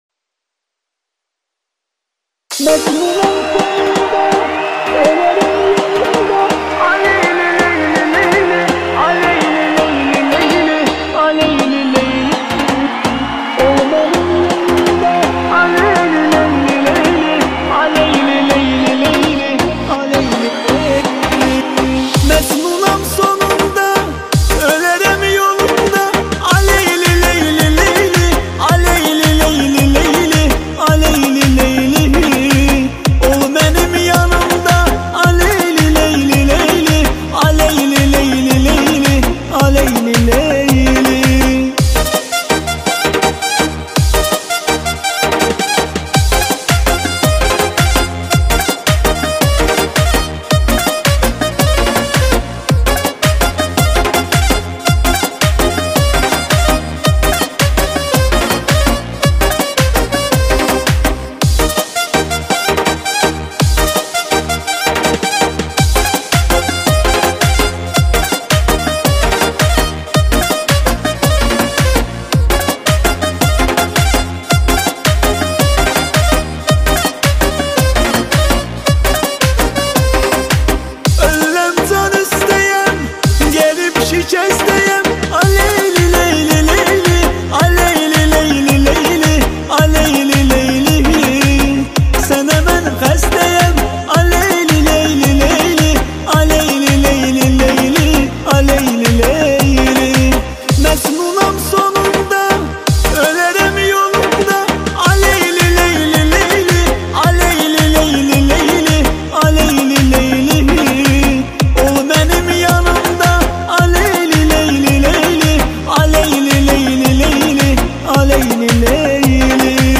با صدای مرد